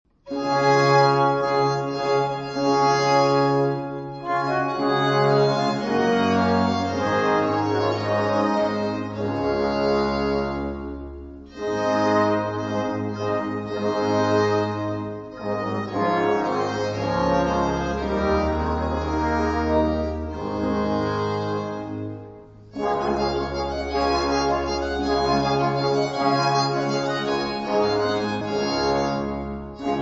• Registrazione sonora musicale